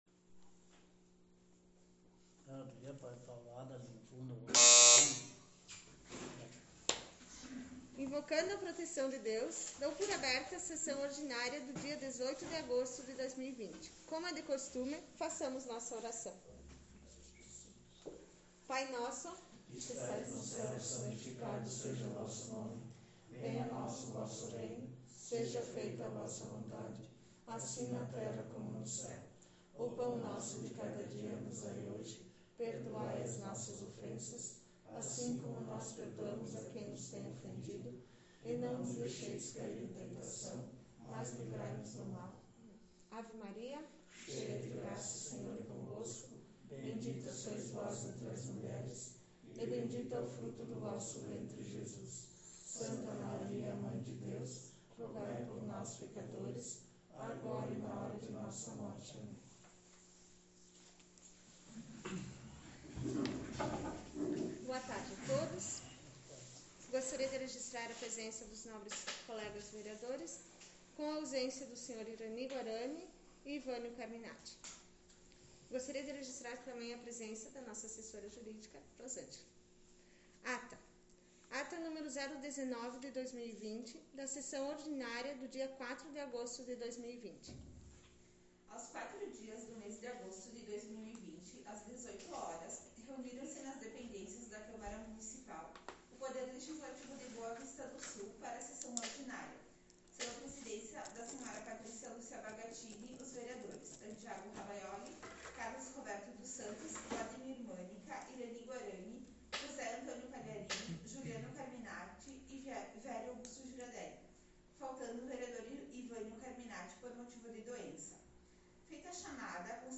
Sessão Ordinária 18/08/2020